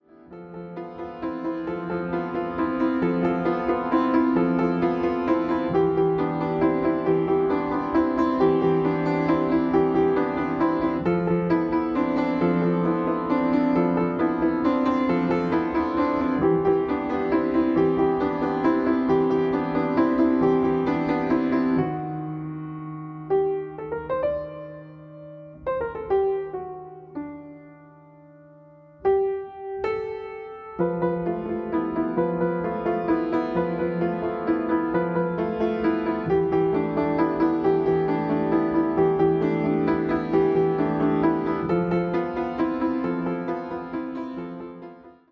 重ねた日々をひとつひとつスケッチしたのような短い曲たち。余韻が深く、沈み込む。